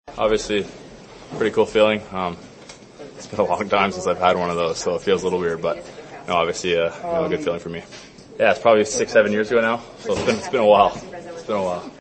Penguins forward Justin Brazeau says he was happy to get his first hat trick in a few years.